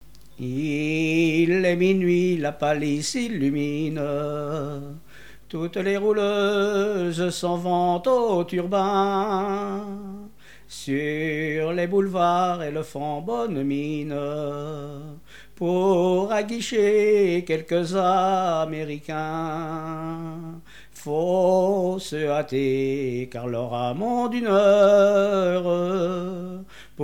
Genre strophique
chansons maritimes contemporaines
Catégorie Pièce musicale inédite